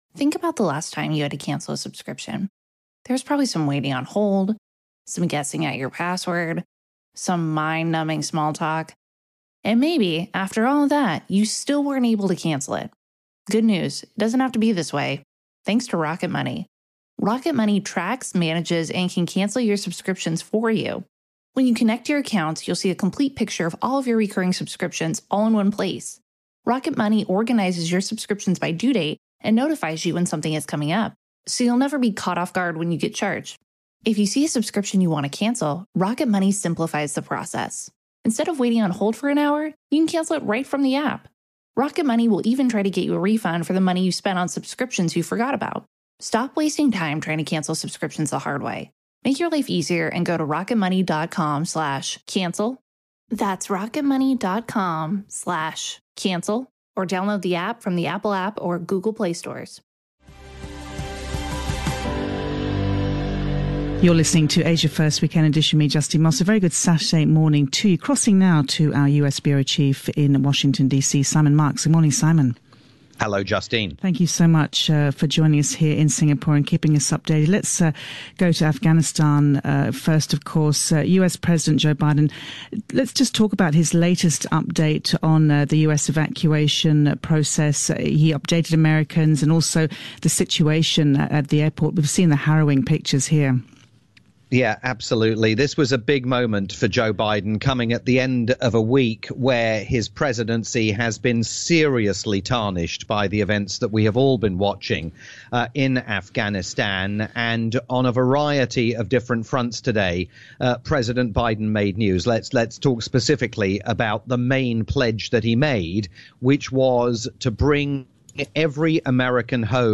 weekly round up